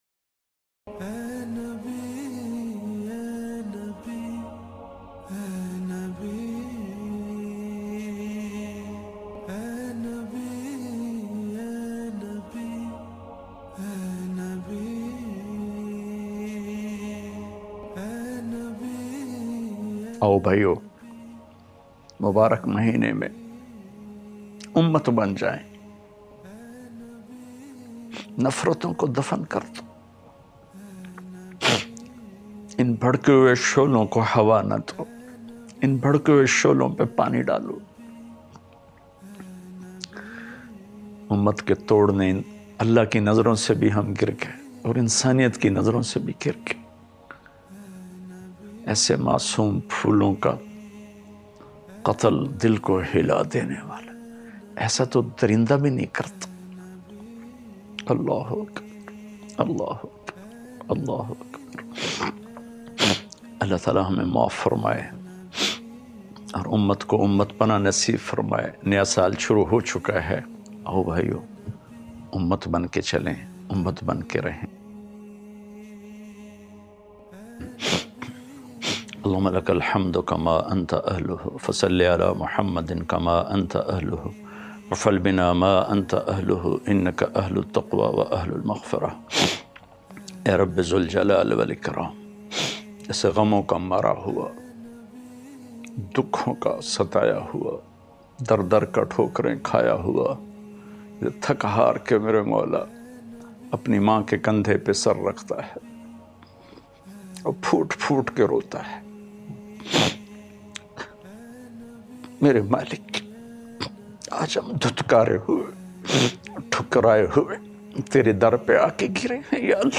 Listen online and download most cryfull dua of Maulana Tariq Jameel in the month of Rabi-ul-Awwal.
Molana-Cry-in-the-Month-of-Rabi-Ul-Awwal.mp3